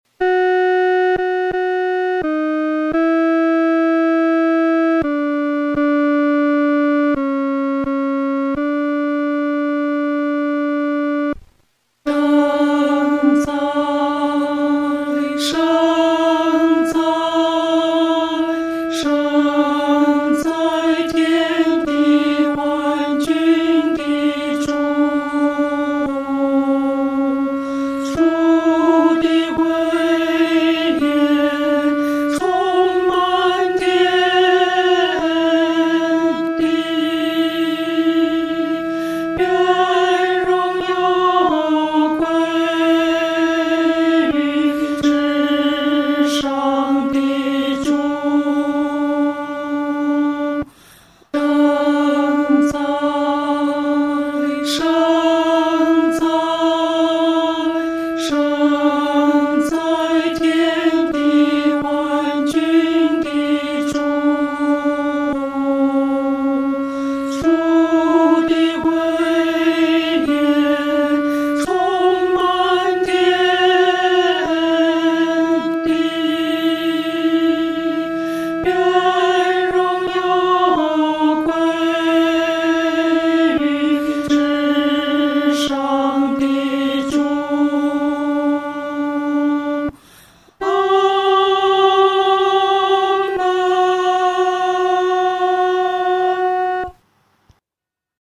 合唱
女低